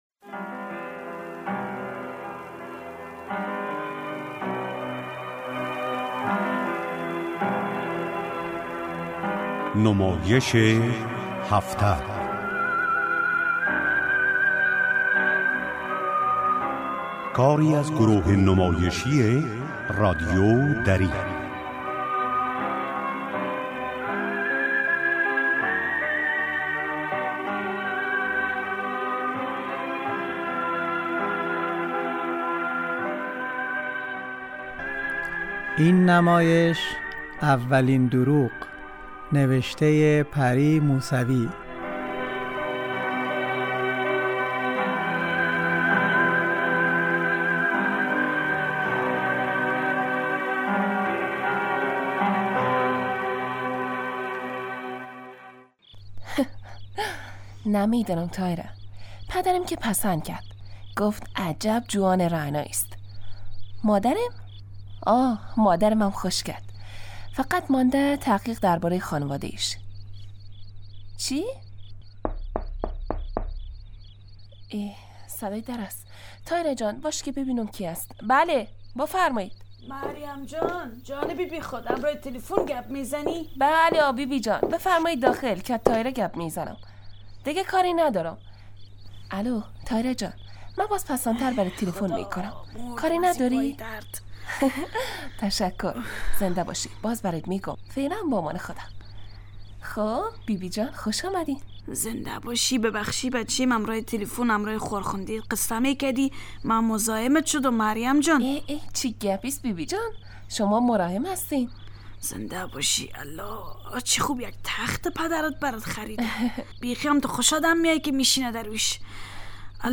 نمایش هفته